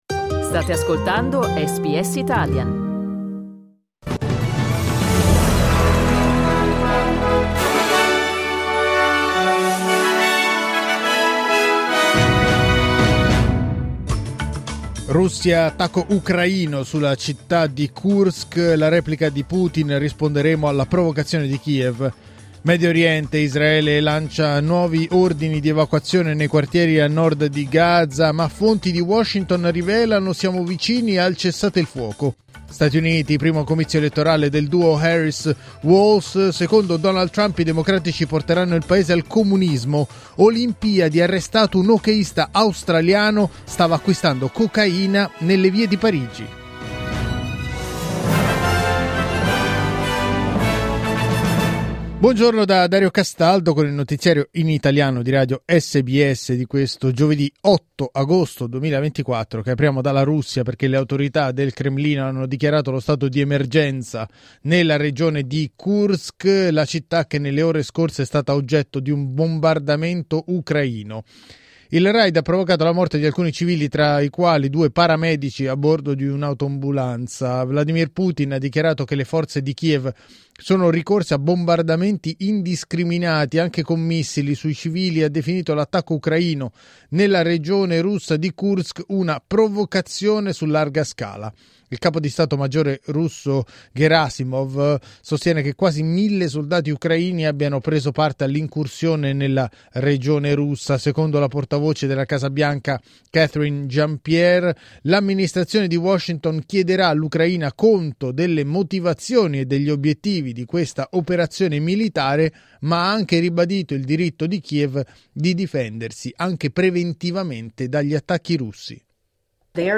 Giornale radio giovedì 8 agosto 2024
Il notiziario di SBS in italiano.